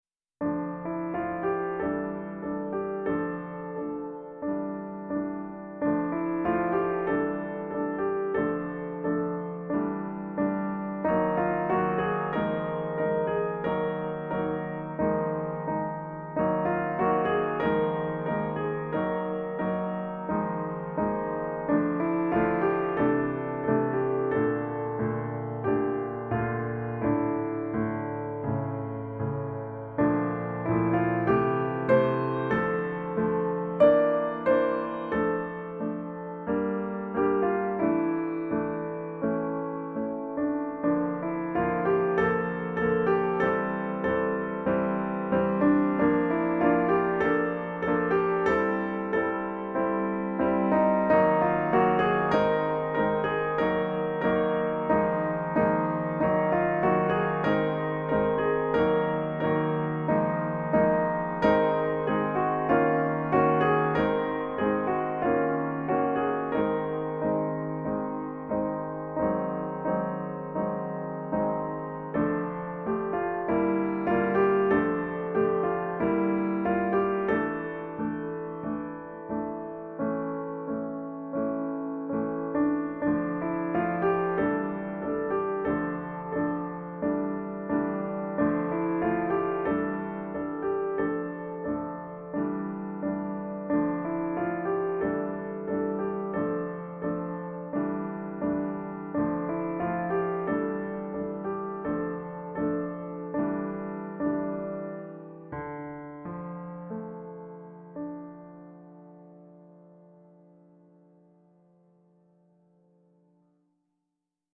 The recurring motif is from the hymn “Let All Mortal Flesh Keep Silence”.